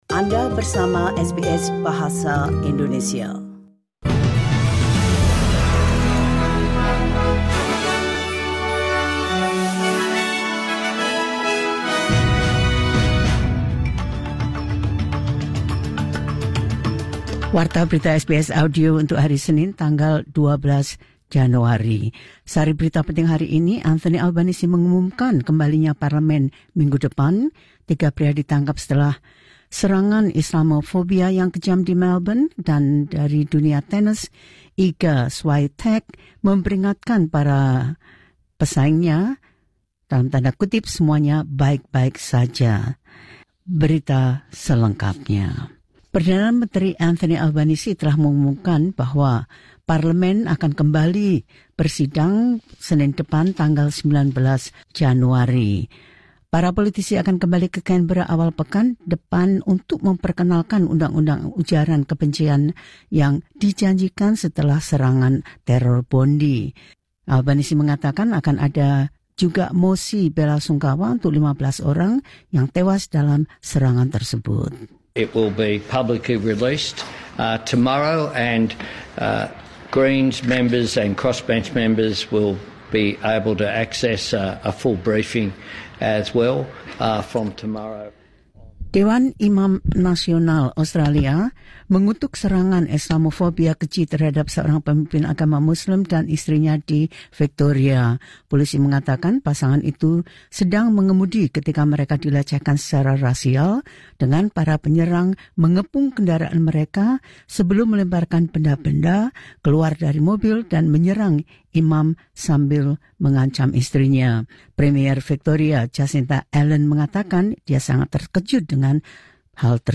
The latest news SBS Audio Indonesian Program – 12 January 2026.